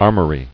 [ar·mor·y]